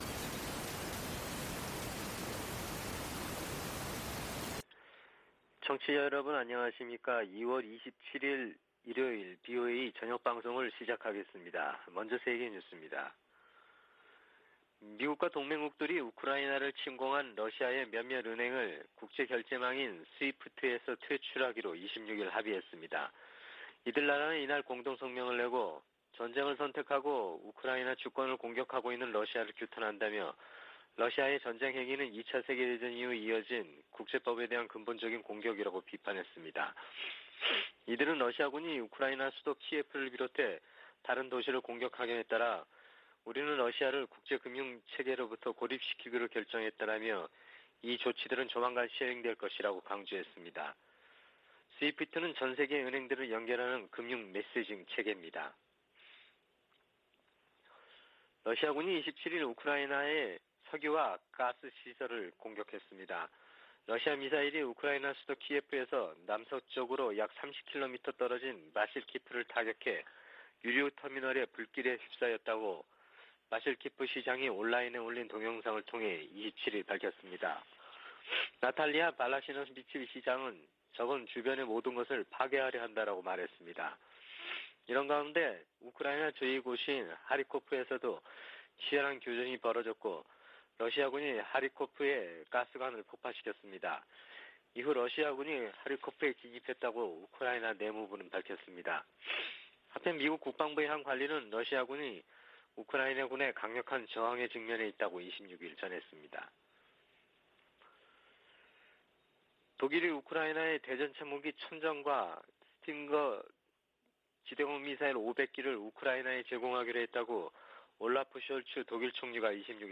VOA 한국어 방송의 일요일 오후 프로그램 1부입니다.